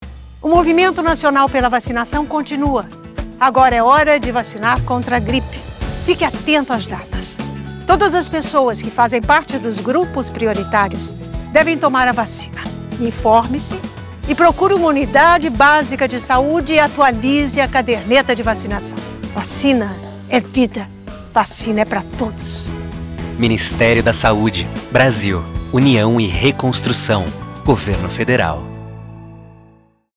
Spot - Vacinação Contra a Gripe - 30seg - mp3